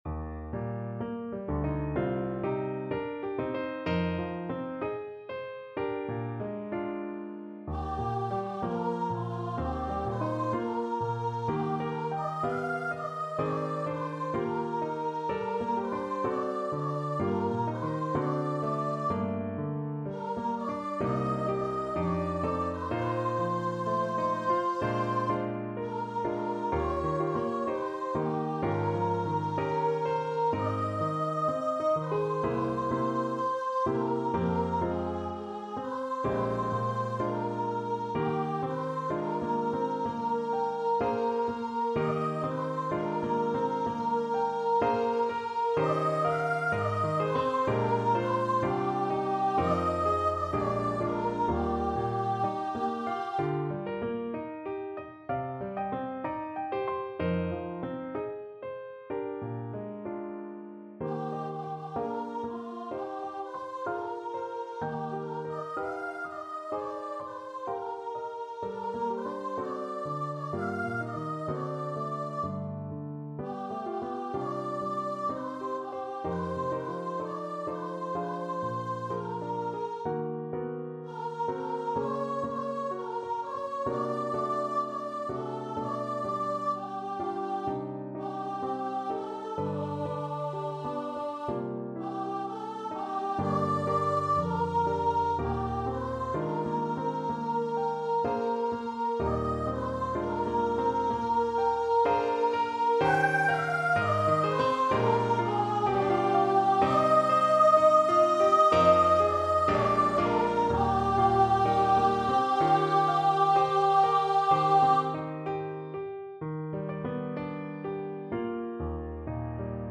4/4 (View more 4/4 Music)
Voice  (View more Intermediate Voice Music)
Classical (View more Classical Voice Music)